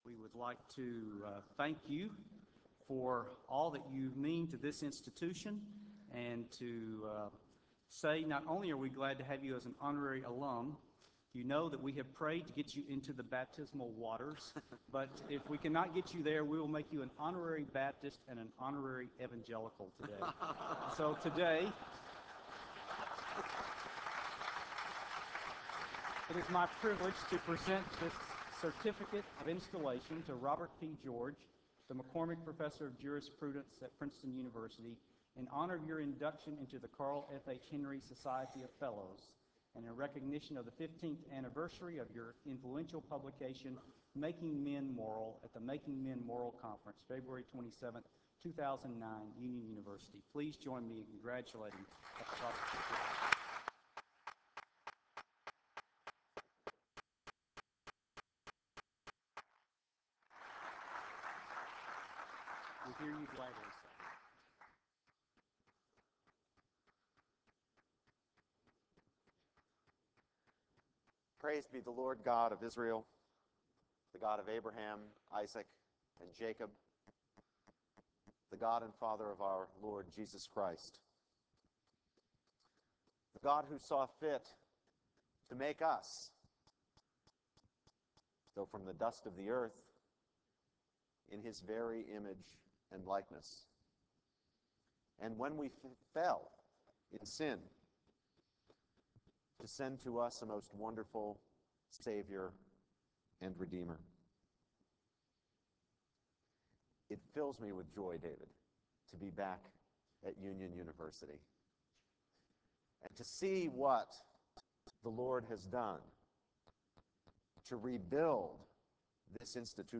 Making Men Moral Chapel: Robert P. George